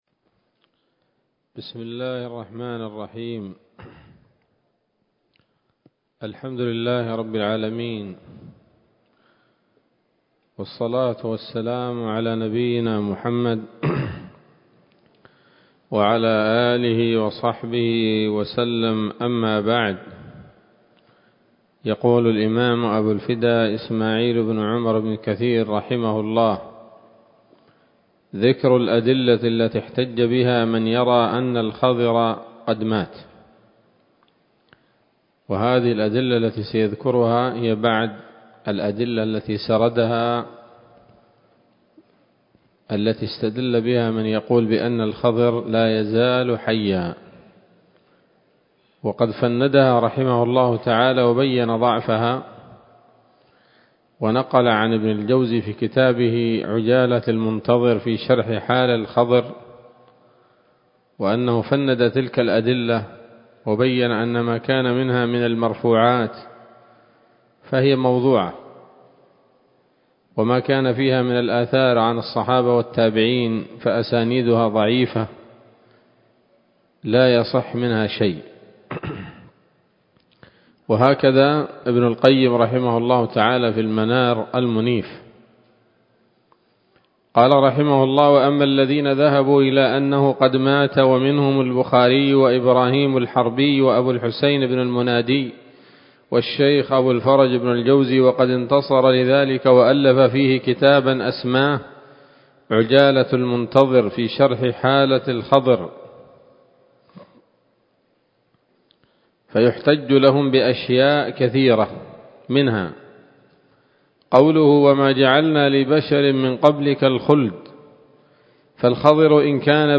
‌‌الدرس الثالث عشر بعد المائة من قصص الأنبياء لابن كثير رحمه الله تعالى